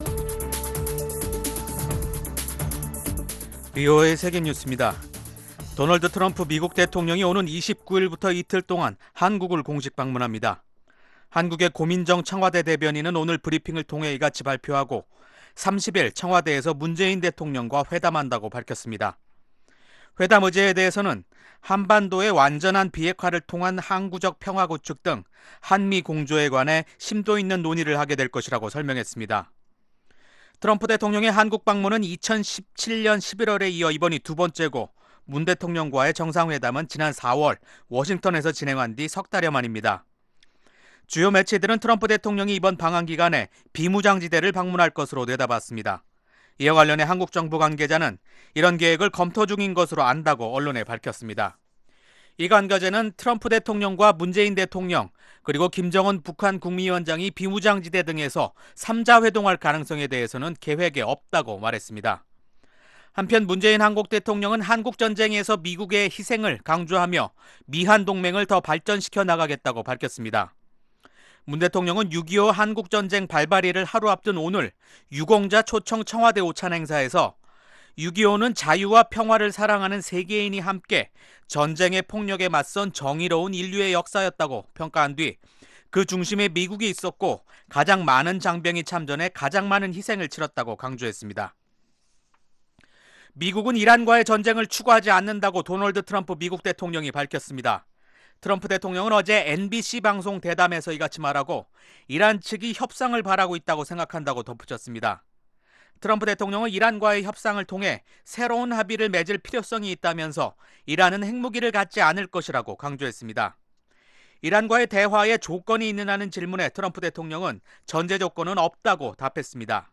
VOA 한국어 간판 뉴스 프로그램 '뉴스 투데이', 2019년 6월 21일 3부 방송입니다. 마이크 폼페오 미국 국무장관이 미-북 실무 협상 재개에 대해 낙관적인 견해를 밝혔습니다. 한국의 전문가들은 미-북 정상 간 친서 외교가 하노이 회담 이후 단절된 양국 간 대화 재개 가능성을 시사한다고 분석했습니다.